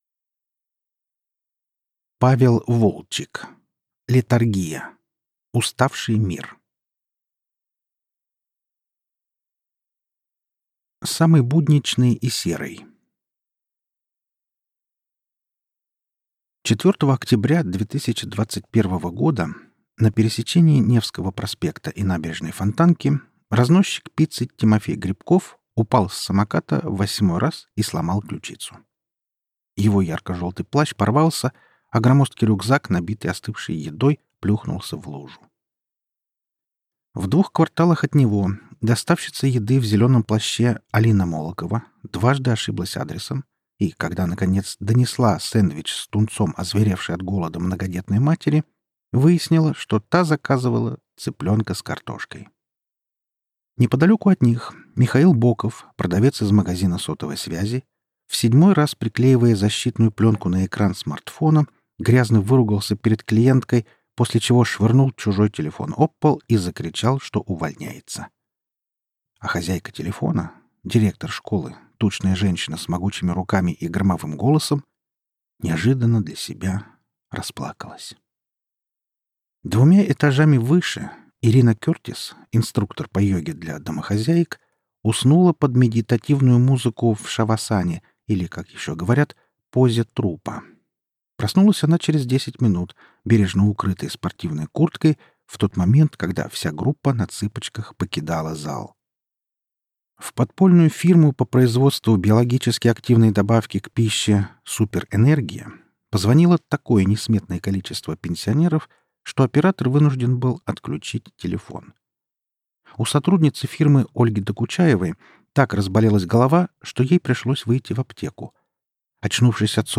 Аудиокнига Летаргия. Уставший мир | Библиотека аудиокниг